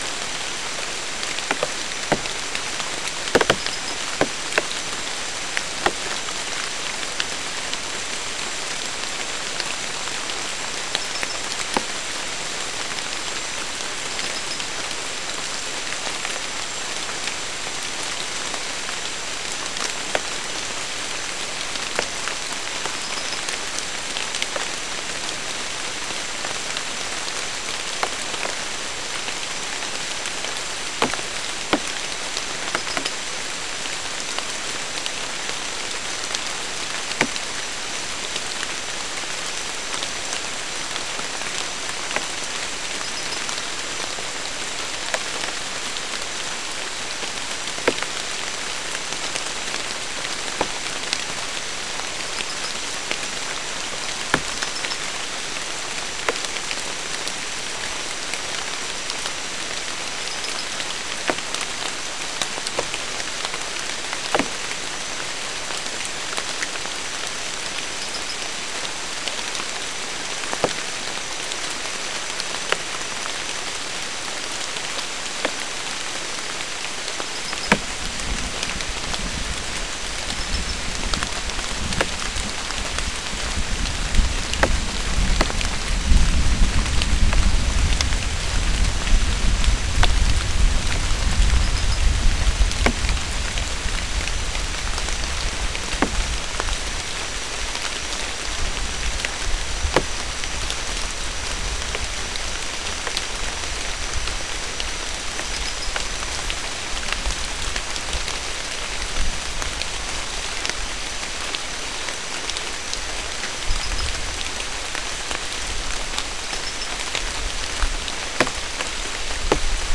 Soundscape Recording Location: South America: Guyana: Kabocalli: 3
Recorder: SM3